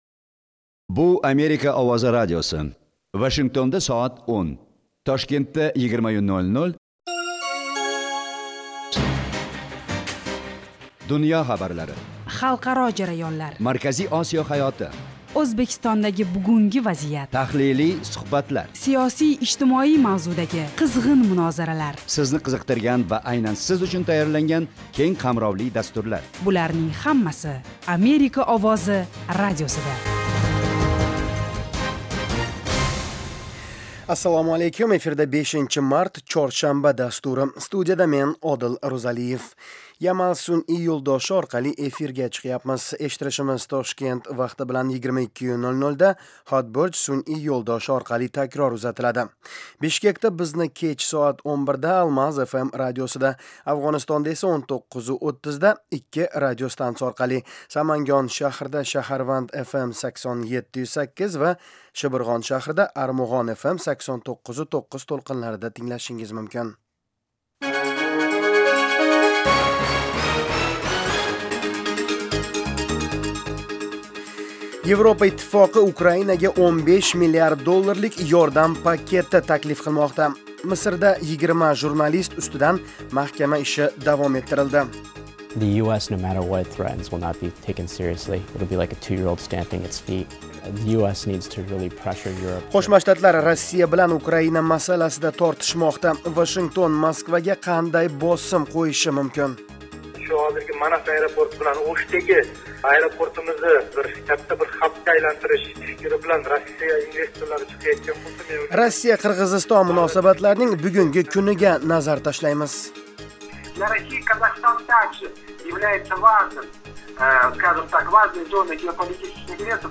Toshkent vaqti bilan har kuni 20:00 da efirga uzatiladigan 30 daqiqali radio dastur kunning dolzarb mavzularini yoritadi. O'zbekiston va butun Markaziy Osiyodagi o'zgarishlarni tahlil qiladi. Amerika bilan aloqalar hamda bu davlat siyosati va hayot haqida hikoya qiladi.